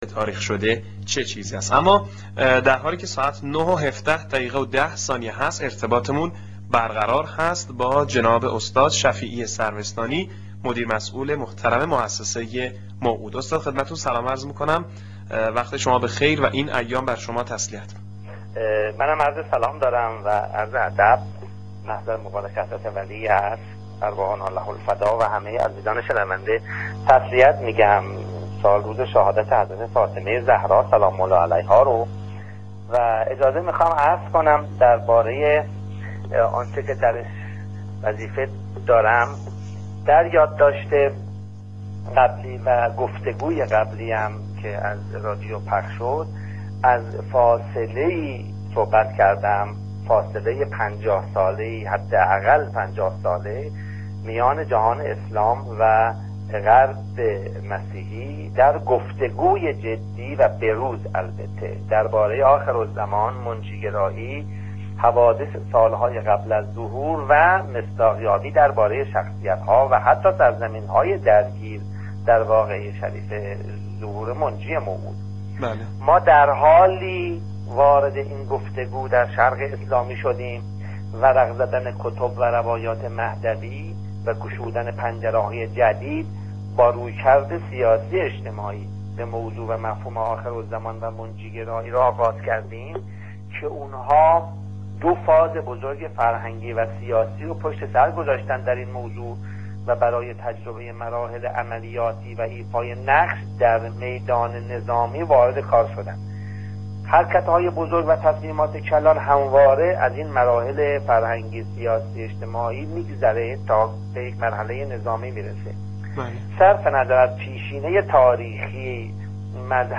فایل صوتی گفتگوی تلفنی برنامه رادیویی تا جمعه ظهور
که صبح های جمعه در رادیو معارف پخش میشود